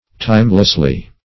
timelessly - definition of timelessly - synonyms, pronunciation, spelling from Free Dictionary Search Result for " timelessly" : The Collaborative International Dictionary of English v.0.48: Timelessly \Time"less*ly\, adv. In a timeless manner; unseasonably.